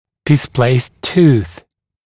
[dɪs’pleɪst tuːθ][дис’плэйст ту:с]смещенный зуб, находящийся не на своем месте, перемещенный зуб